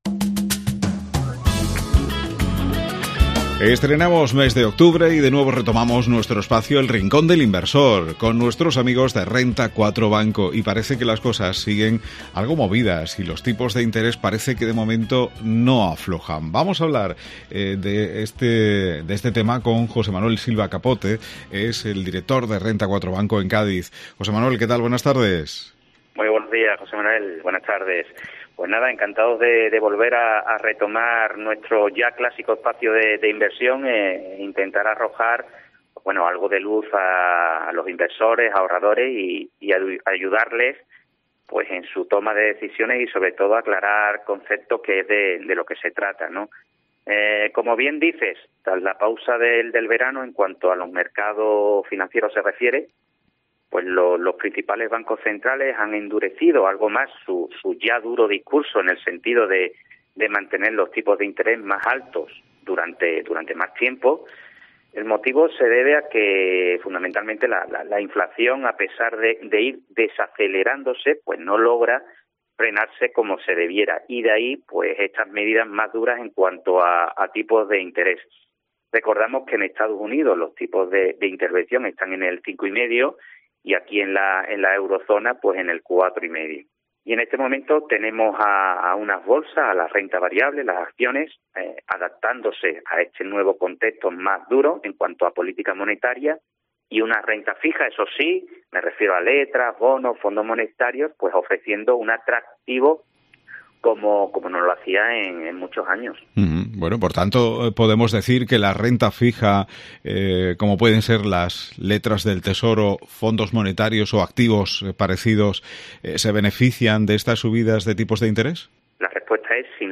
¿Por tanto podemos decir que la Renta Fija como puedan ser las letras del tesoro, fondos monetarios o activos parecidos se benefician de estas subidas de tipos de interés?